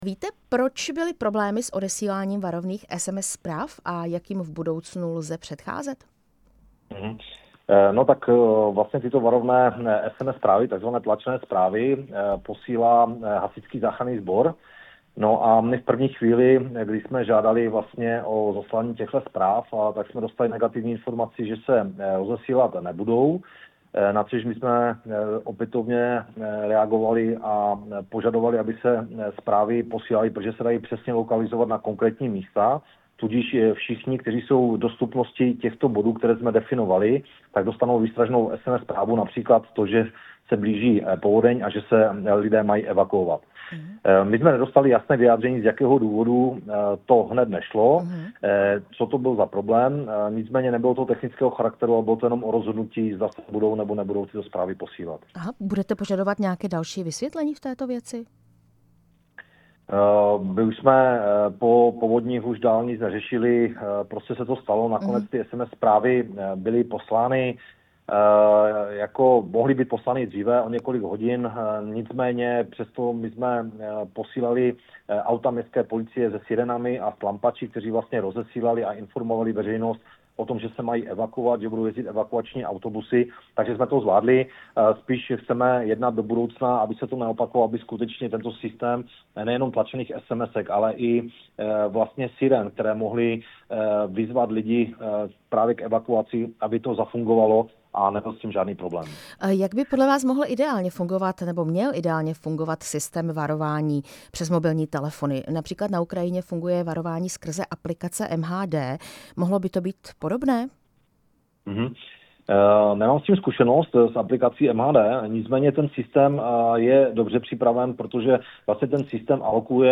Půl roku od ničivých povodní, které loni v září zasáhly Moravskoslezský kraj, krizové štáby měst a obcí analyzují své zkušenosti. Bližší informace uvedl pro Rádio Prostor opavský primátor Tomáš Navrátil z hnutí ANO.
Rozhovor s primátorem Opavy Tomášem Navrátilem (ANO)